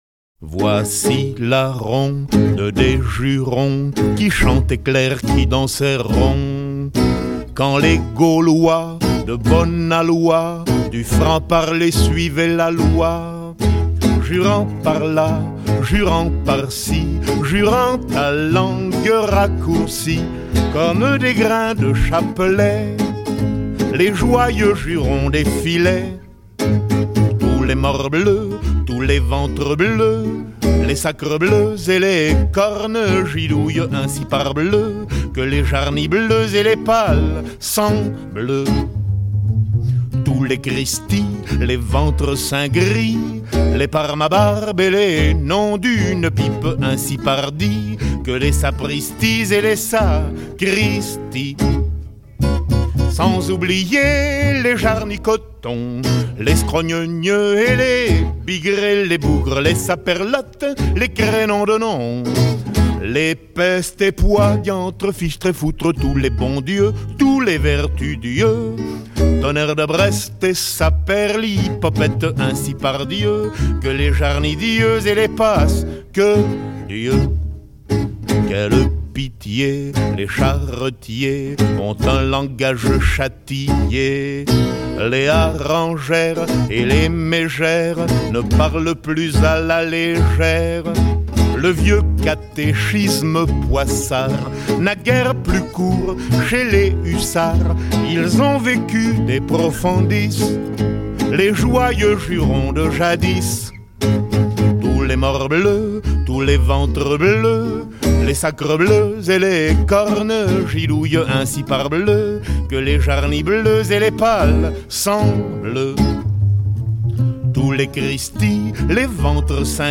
Auteurs-compositeurs-interprètes